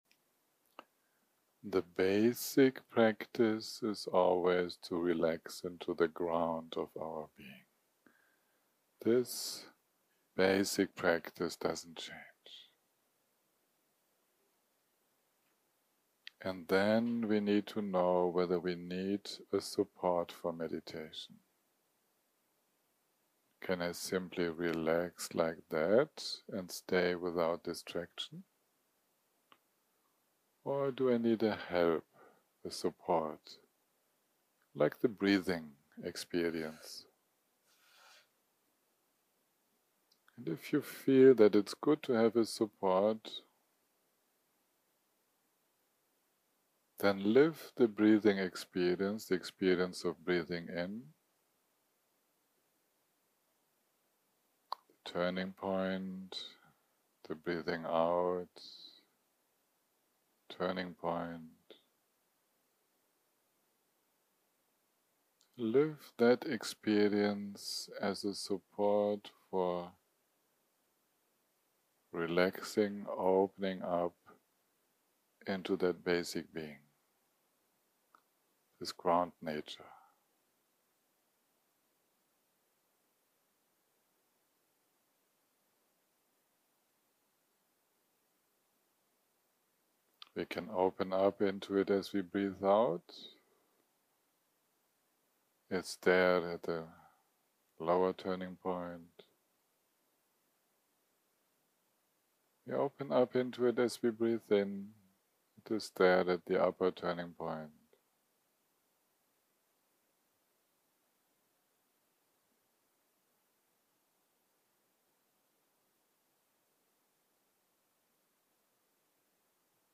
יום 2 - הקלטה 6 - צהרים - מדיטציה מונחית
סוג ההקלטה: מדיטציה מונחית